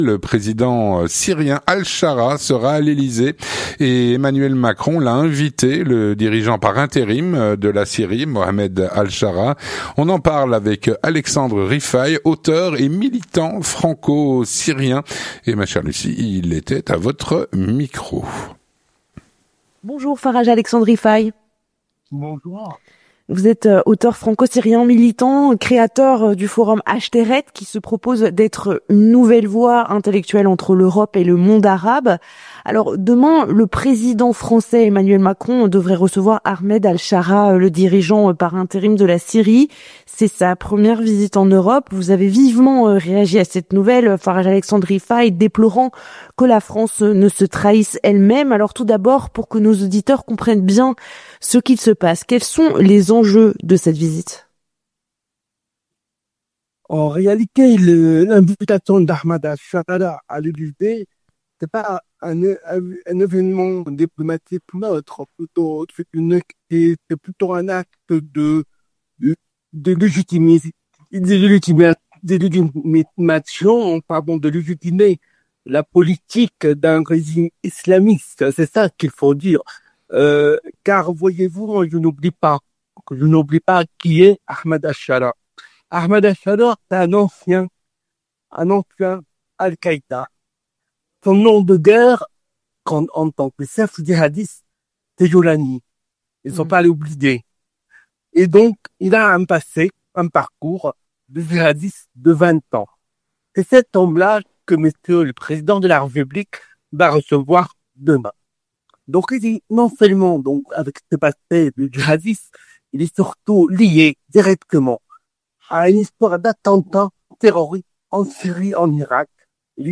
L'entretien du 18H - Emmanuel Macron a invité le Président syrien, Mohammad Al Shara, à l’Elysée.